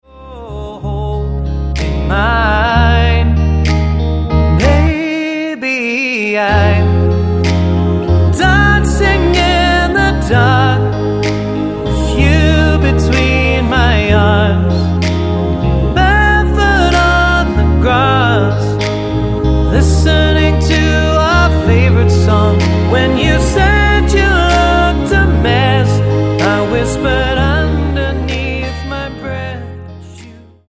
--> MP3 Demo abspielen...
Tonart:Ab Multifile (kein Sofortdownload.